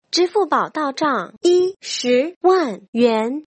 在线生成支付宝到账ZB语音